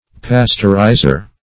Search Result for " pasteurizer" : The Collaborative International Dictionary of English v.0.48: Pasteurizer \Pas"teur*iz`er\, n. One that Pasteurizes, specif. an apparatus for heating and agitating, fluid.
pasteurizer.mp3